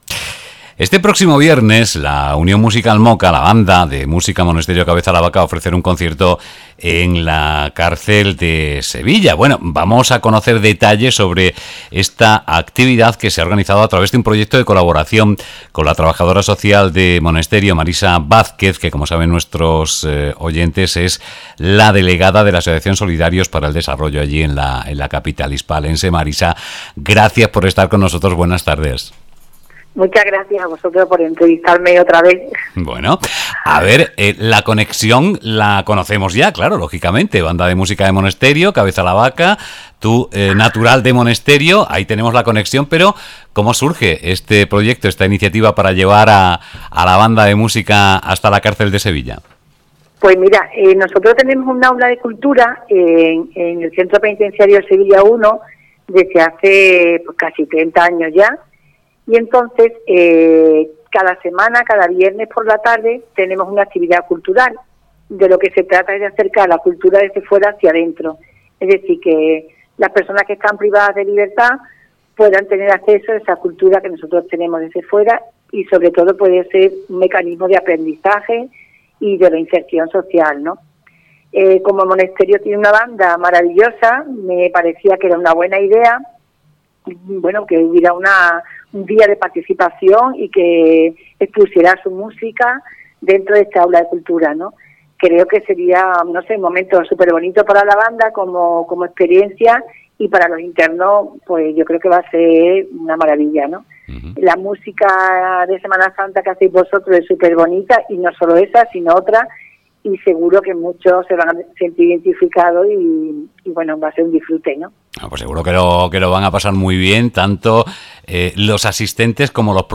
La Unión Musical MOCA (Monesterio – Cabeza la Vaca) ofrece un concierto en la cárcel de Sevilla
79tiXBANDAENLACRCELDESEVILLA.mp3